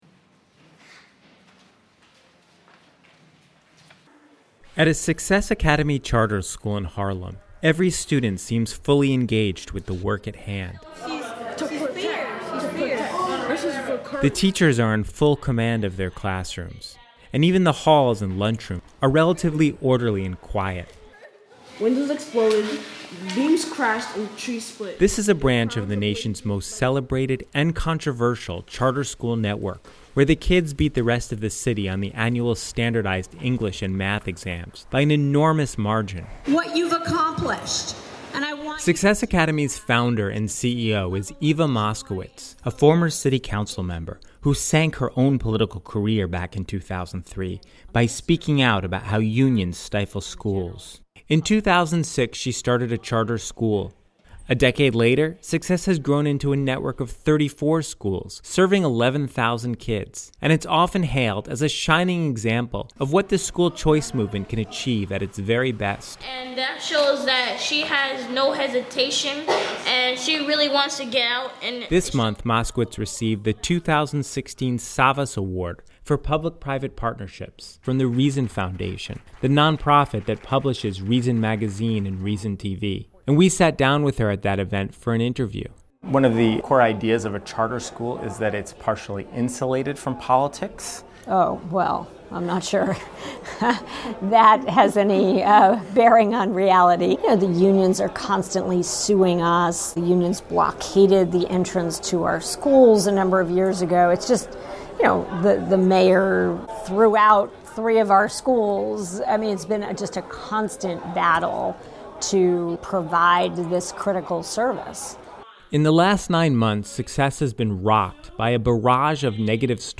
At that event, we sat down with Moskowitz for an interview.